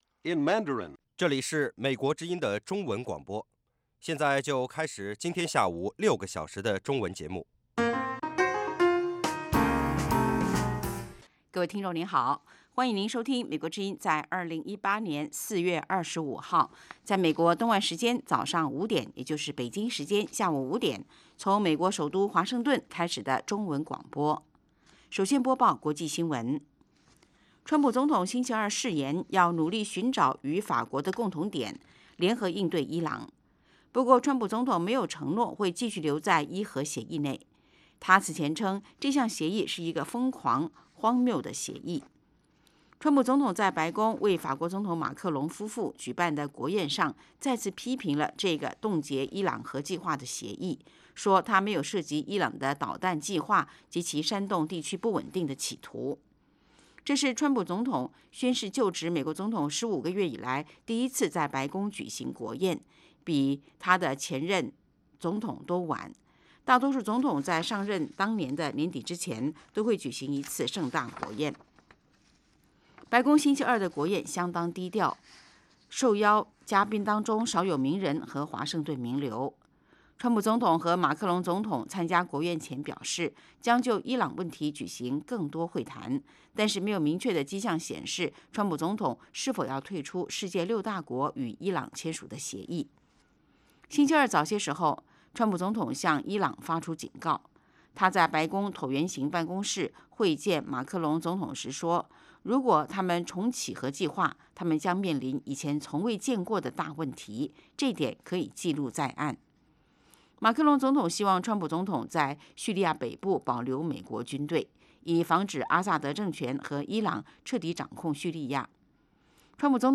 北京时间下午5-6点广播节目。广播内容包括国际新闻，收听英语，以及《时事大家谈》(重播)